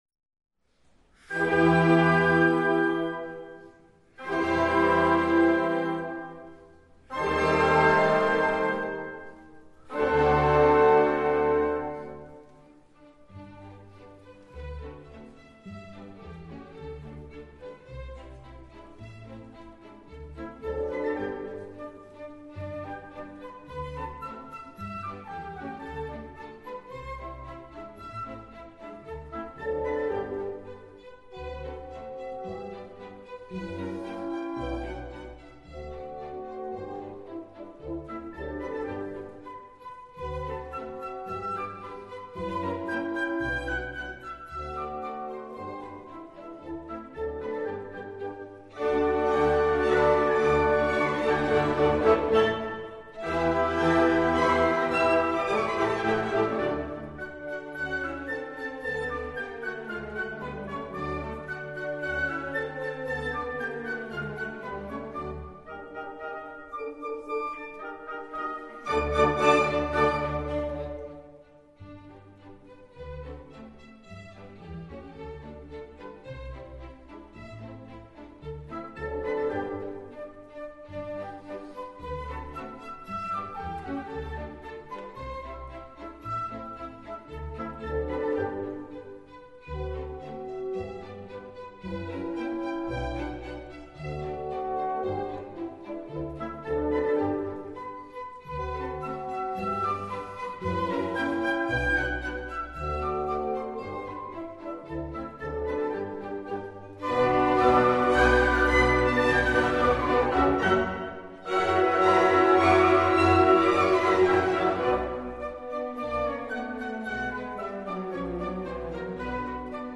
In einem vormittäglichen Sonderkonzert wurden ergänzend folgende drei Stücke zu Gehör gebracht und als Zugabe zur Gesamtaufnahme beigegeben
Supplément / Ergänzung No.5bis Pas de deux I Andante et II Andante maestoso et I [suite] Allegretto 8:44 Min No.15a Pas des troi et Cheur tyrolien (version originale) choeur —› Allegretto: À nos chants...